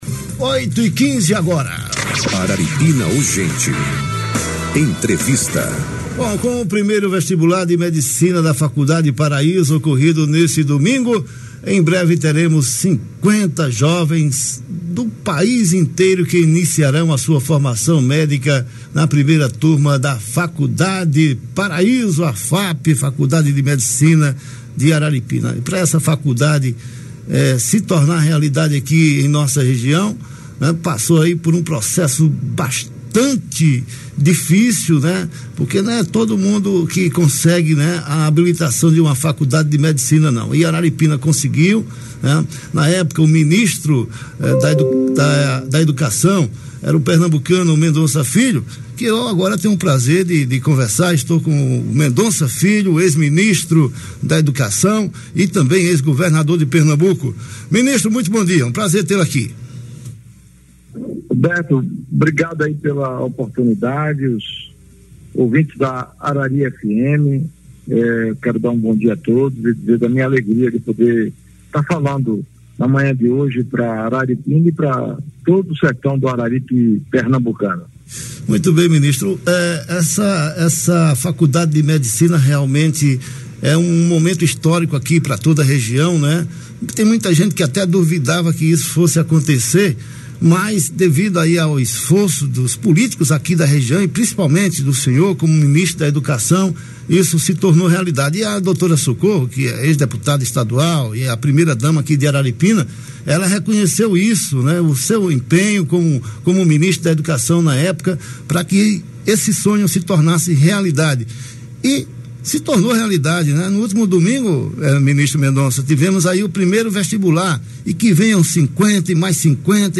Na entrevista que concedeu nessa terça (13) na Arari FM, o ex-ministro da Educação destacou a luta do prefeito Pimentel, do vice Evilásio e da ex-deputada Socorro Pimentel para essa grande conquista